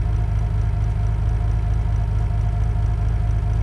rr3-assets/files/.depot/audio/Vehicles/w12_01/w12_01_idle.wav
w12_01_idle.wav